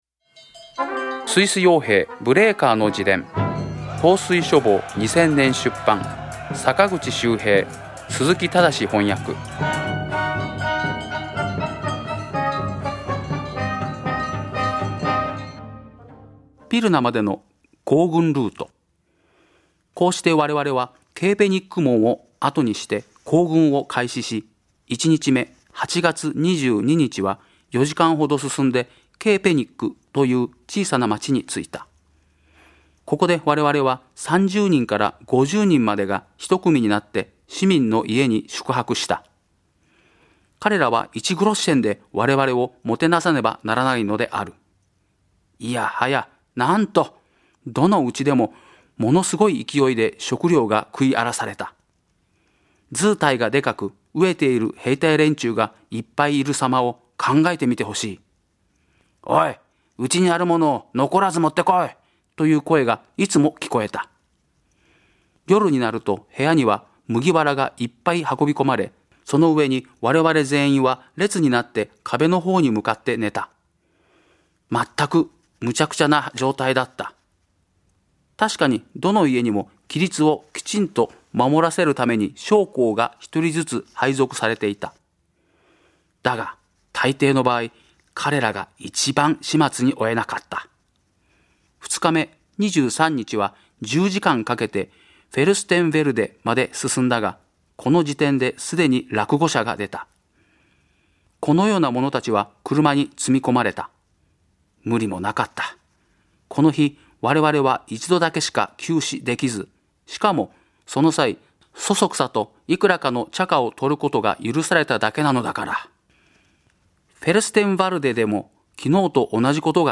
朗読『スイス傭兵ブレーカーの自伝』第55回